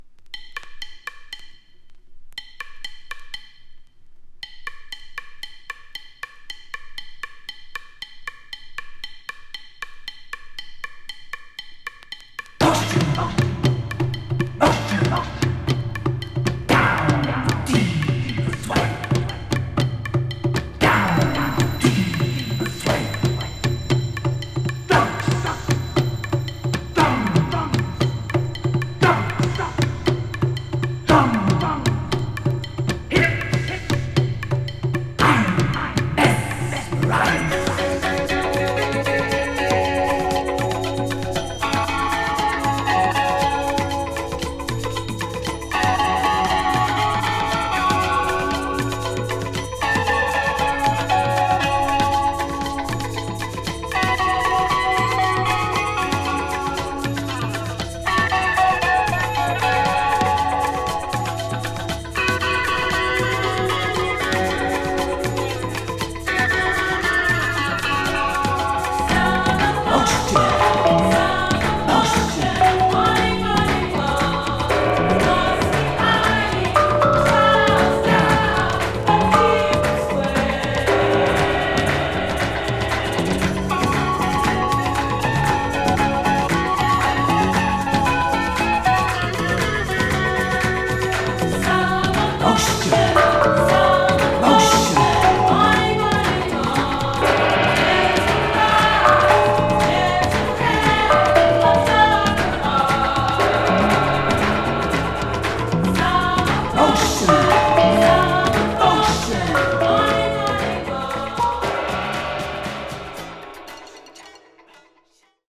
> ELECTRO/NEW WAVE